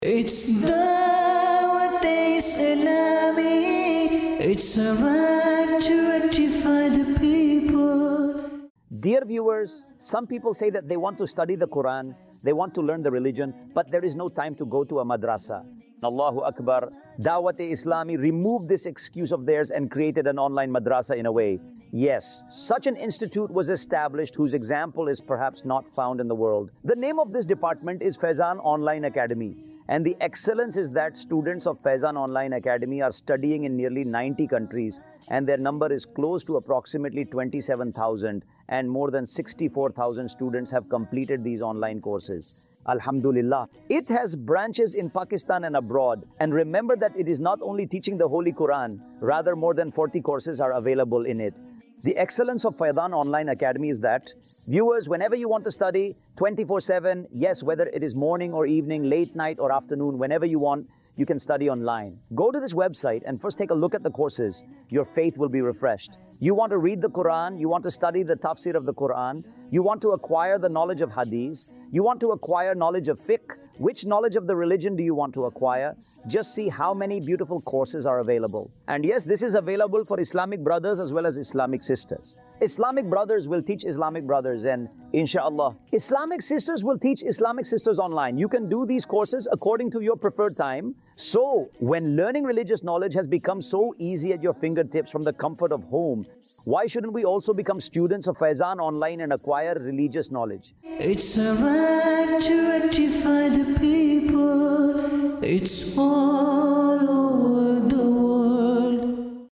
Faizan Online Acadmy | Department of Dawateislami | Documentary 2026 | AI Generated Audio
فیضان آن لائن اکیڈمی | شعبہِ دعوت اسلامی | ڈاکیومینٹری 2026 | اے آئی جنریٹڈ آڈیو